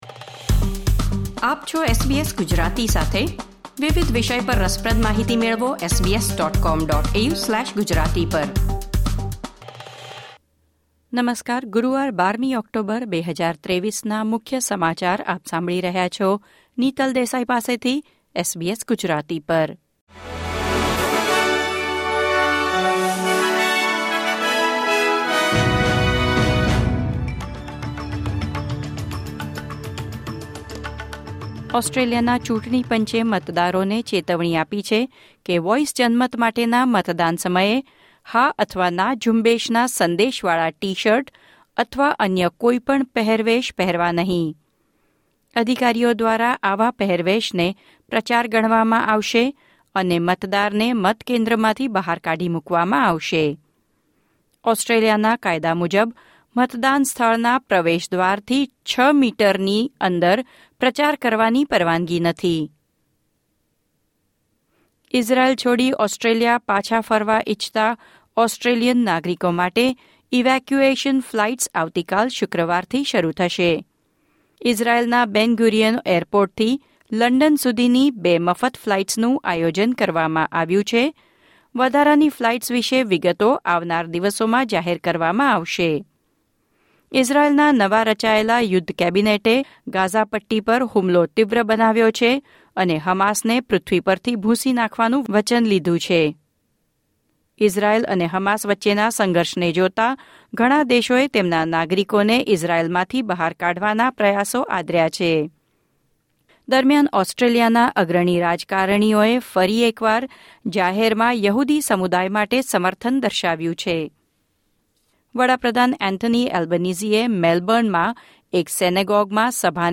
SBS Gujarati News Bulletin 12 October 2023